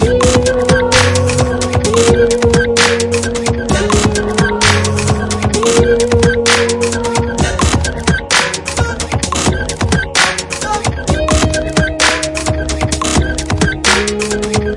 Tag: 寒意 旅行 电子 舞蹈 looppack 样品 毛刺 节奏 节拍 低音 实验 器乐